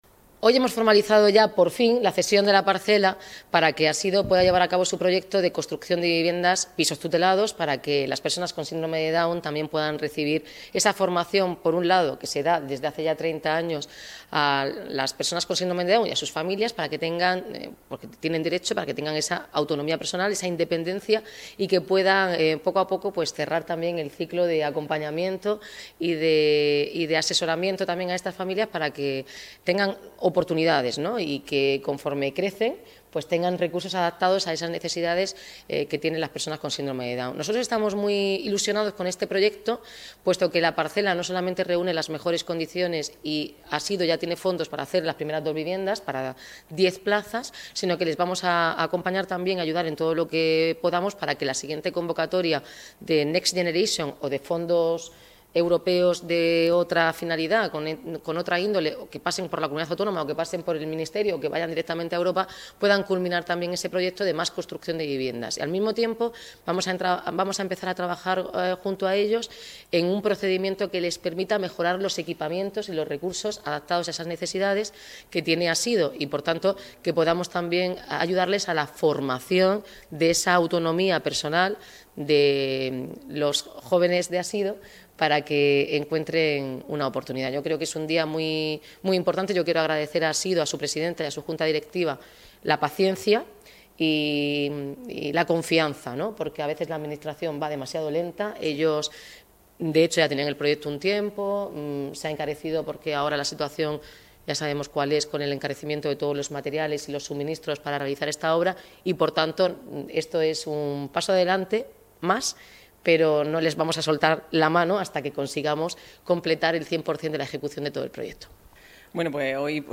Declaraciones de Noelia Arroyo